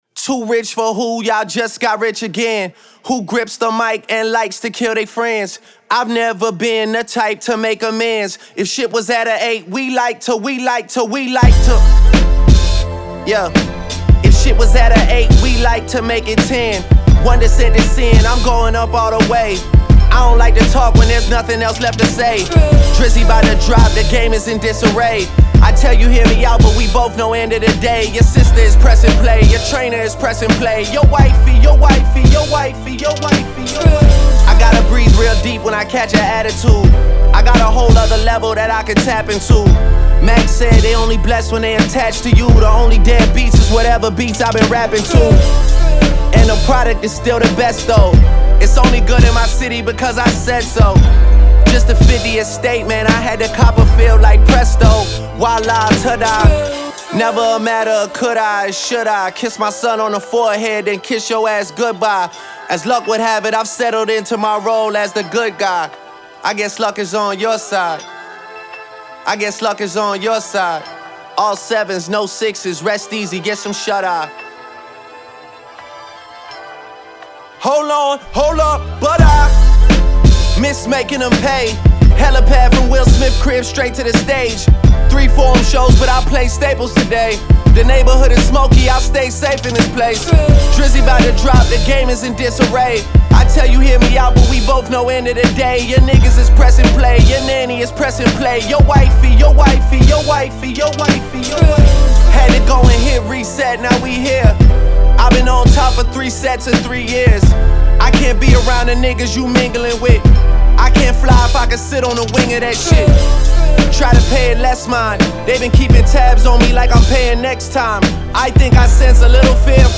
Genre : Hip-Hop